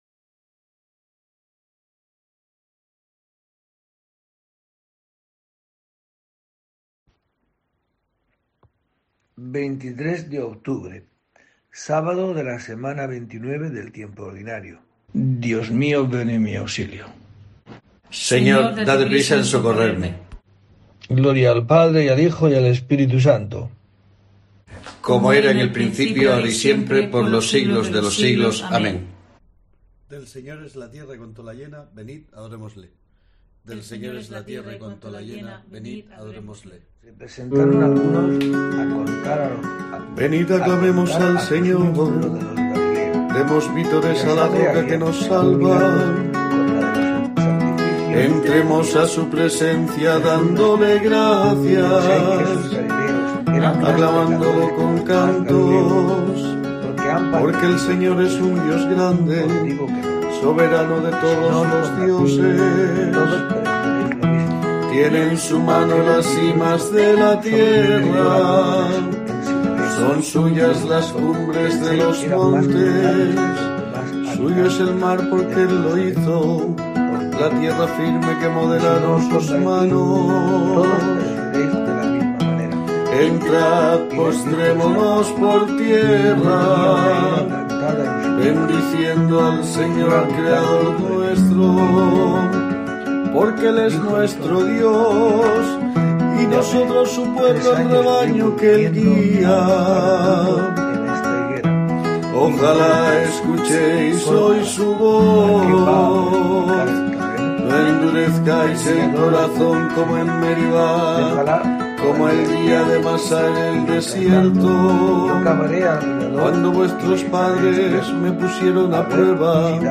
En este día tiene lugar una nueva oración en el rezo de Laudes, marcado por la nueva normalidad.